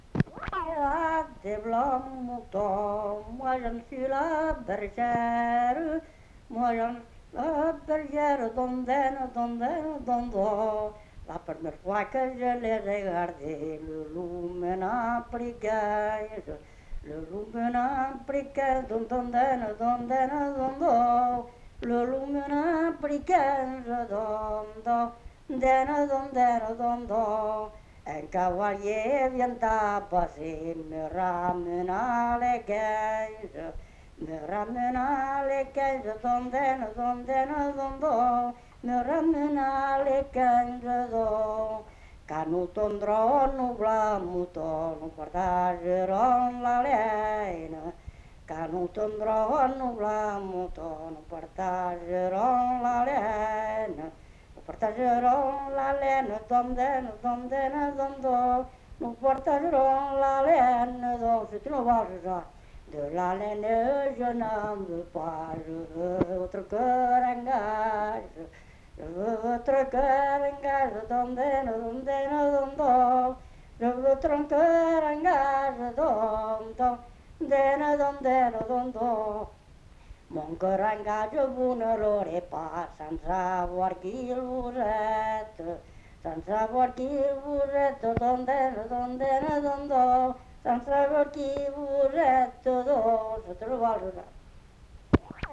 Genre : chant
Type de voix : voix d'homme
Production du son : chanté
Danse : valse